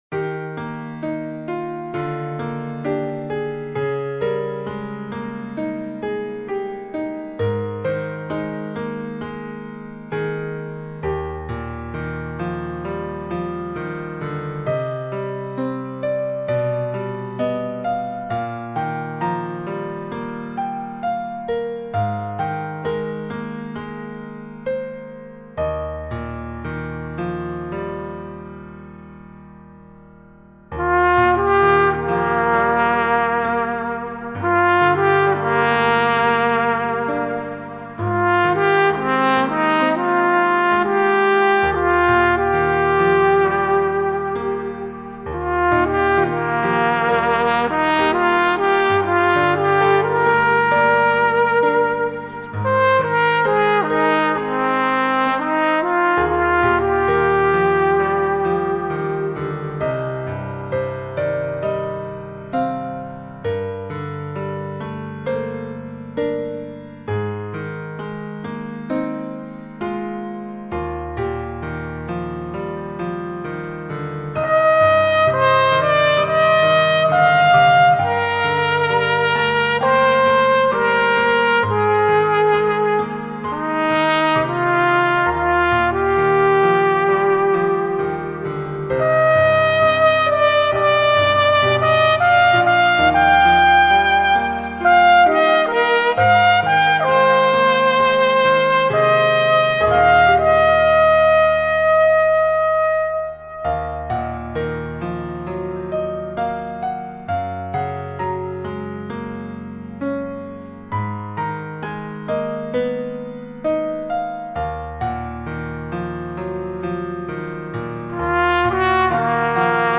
Audio clip trumpet + piano
Dieses langsame Stück für Solo-Trompete